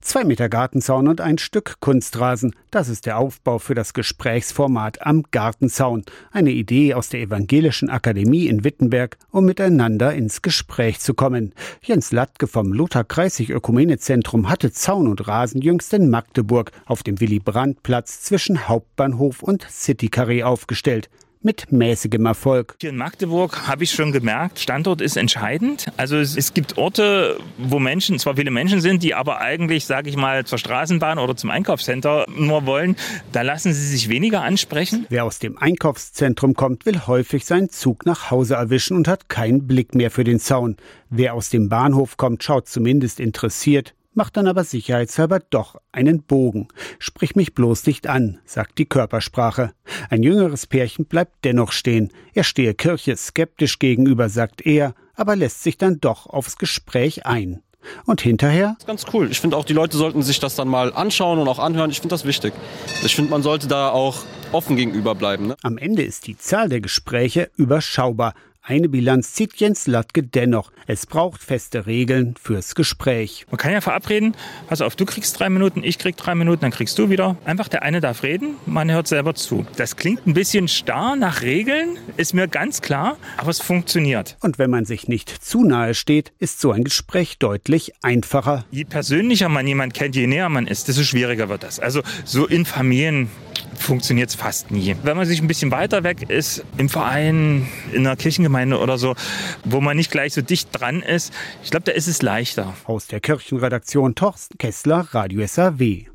Junger Mann